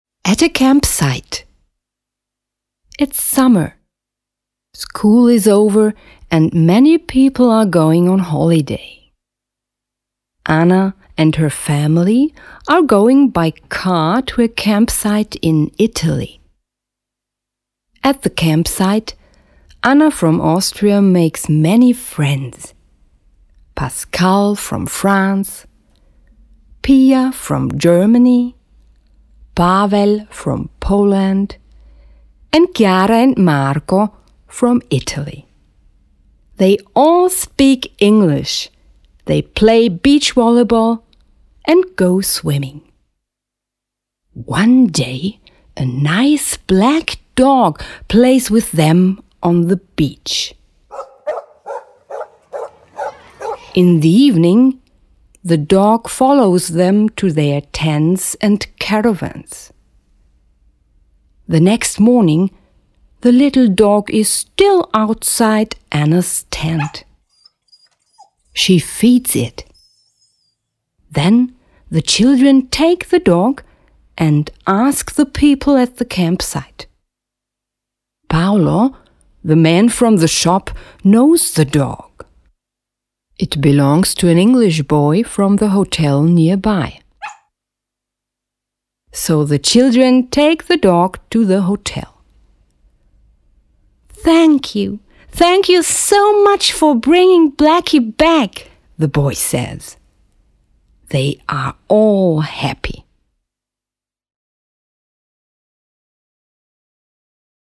At a campsite (story)